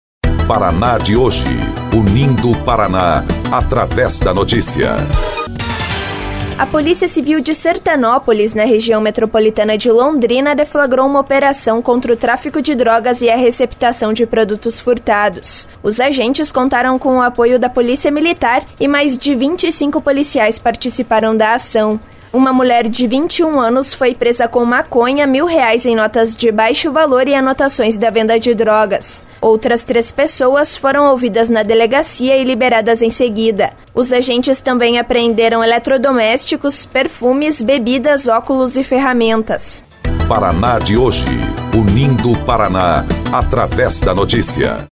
BOLETIM – Operação investiga tráfico e receptação de produtos furtados em Sertanópolis